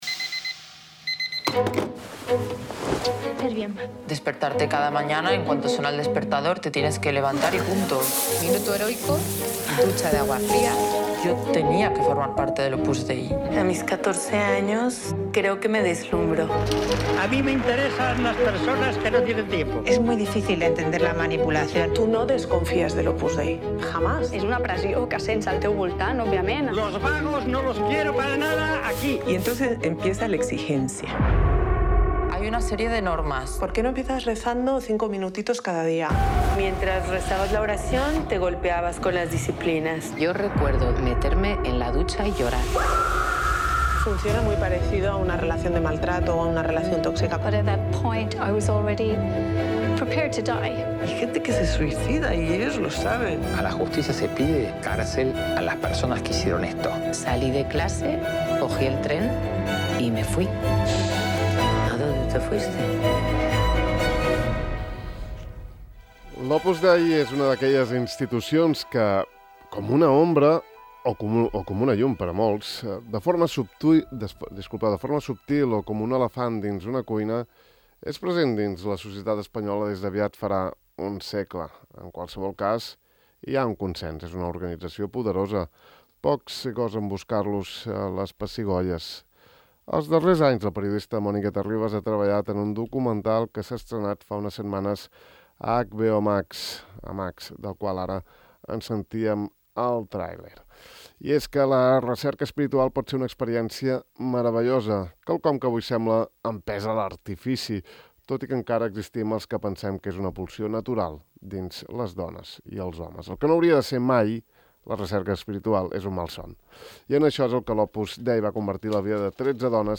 Mònica Terribas presenta a Ràdio Illa la docusèrie ‘El minuto heroico: yo también dejé el Opus Dei‘, en què la periodista recull el testimoni de tretze dones que durant la seva pertinença a aquesta organització catòlica patiren un tracte abusiu en àmbit laboral, psicològic i espiritual.